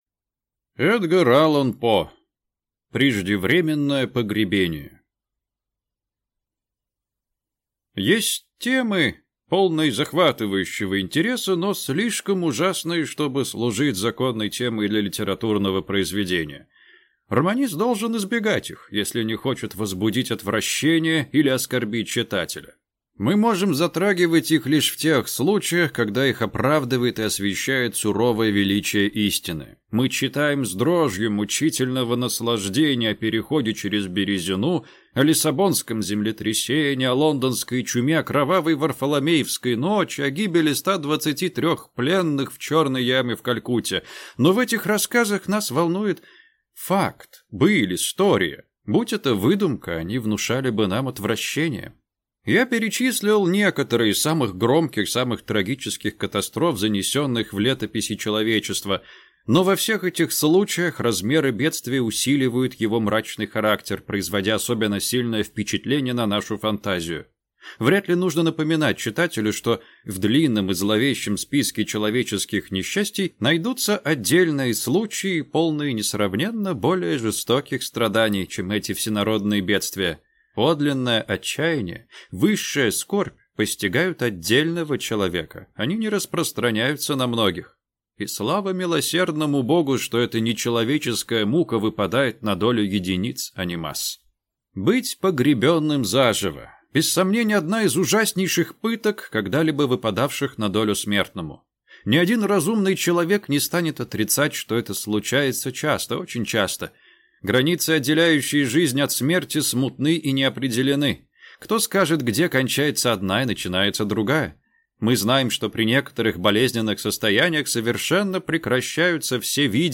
Аудиокнига Преждевременное погребение | Библиотека аудиокниг